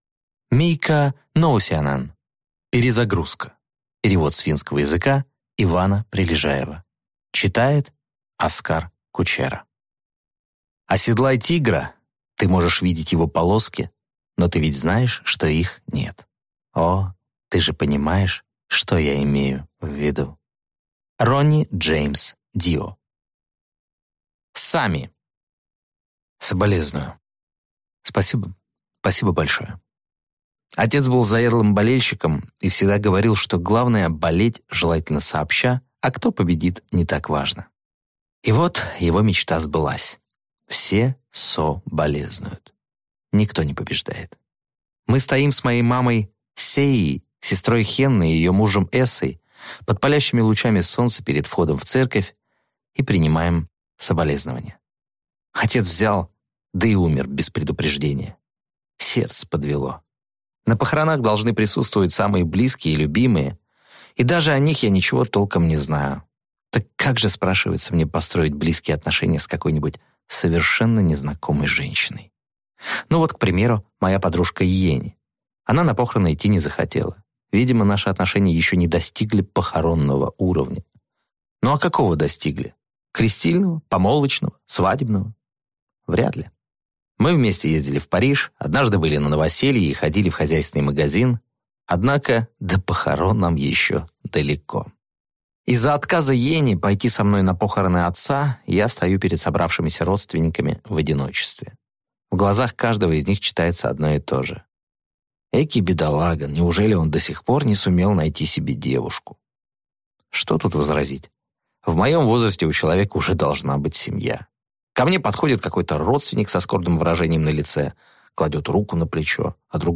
Аудиокнига Перезагрузка | Библиотека аудиокниг